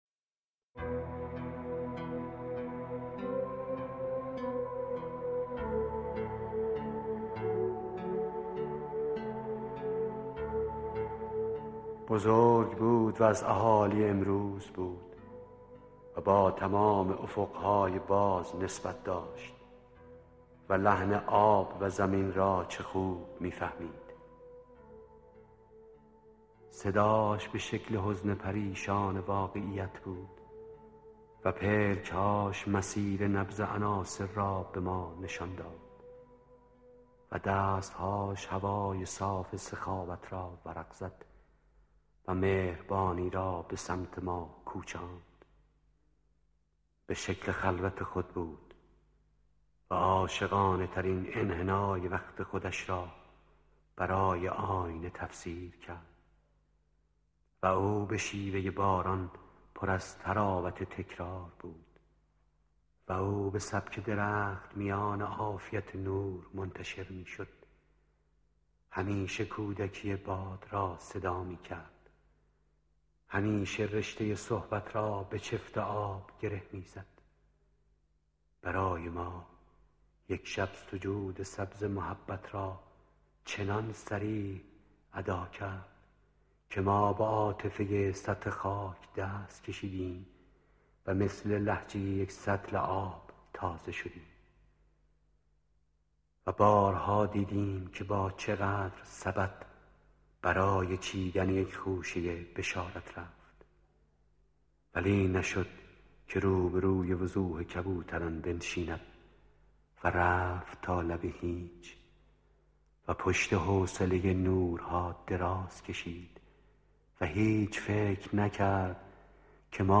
بزرگ بود (با صدای احمدرضا احمدی)
صوت بزرگ بود (با صدای احمدرضا احمدی) از شاعر سهراب سپهری در نشریه وزن دنیا